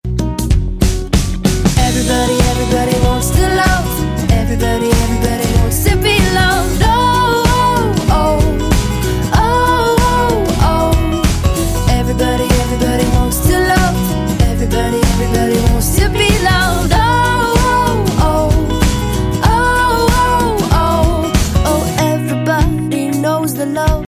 • Качество: 128, Stereo
поп
женский вокал